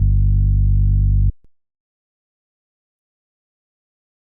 Bass (3).wav